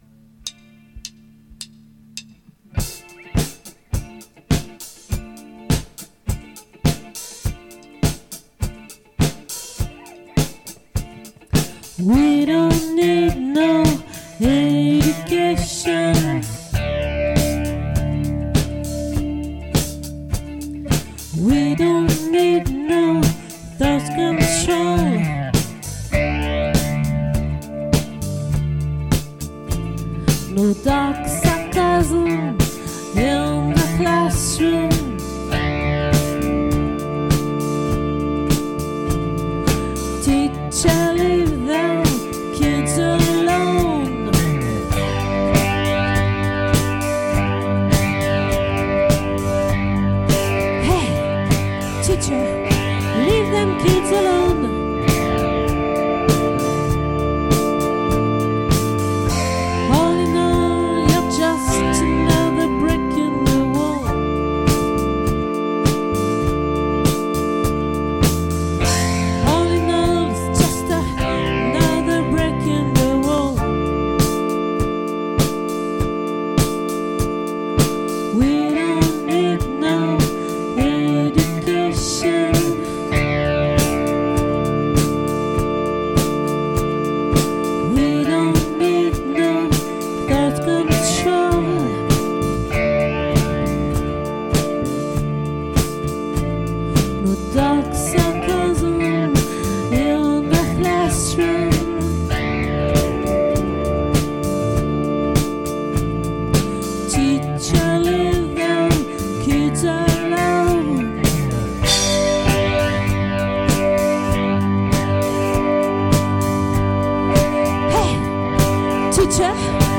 🏠 Accueil Repetitions Records_2023_01_04_OLVRE